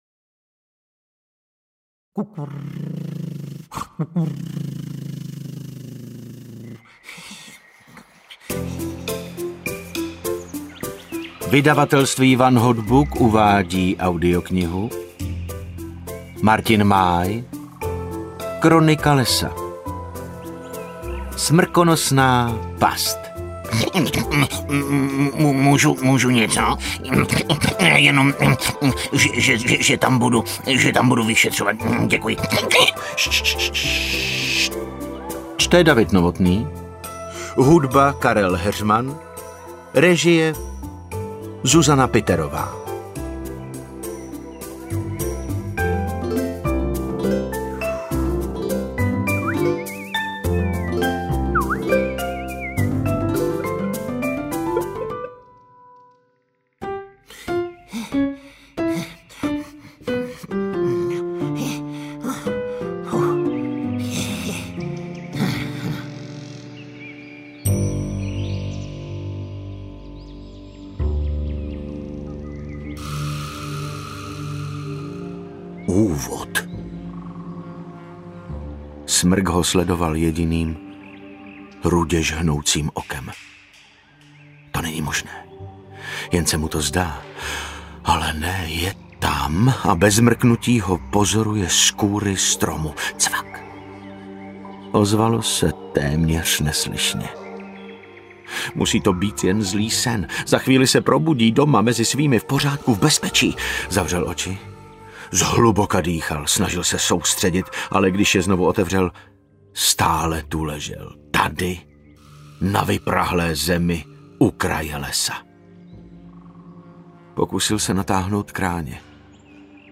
Interpret:  David Novotný
AudioKniha ke stažení, 29 x mp3, délka 5 hod. 34 min., velikost 302,0 MB, česky